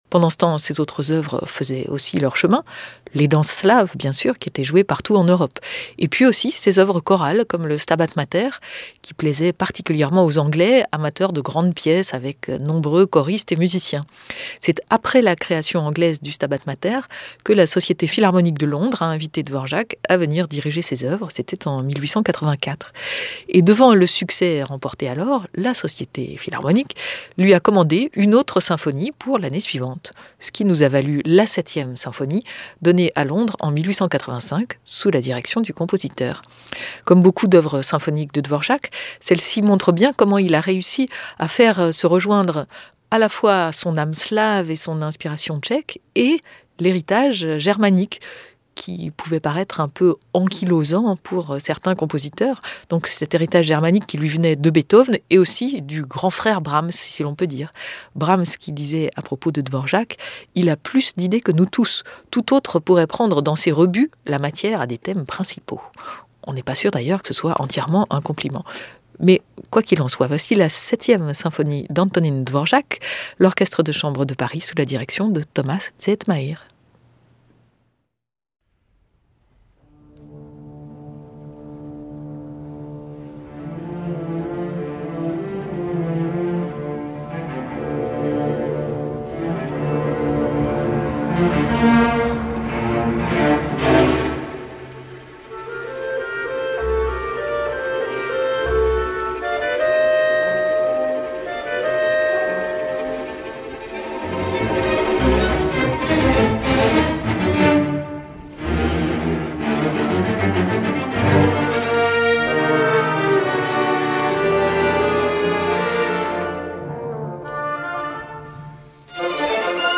Over to Paris this week for a concert by Orchestre de Chambre de Paris, conducted by Thomas Zehetmair and featuring Andreas Staier on Harpsichord. The Concert was recorded by Radio France Musique on January 22nd of this year.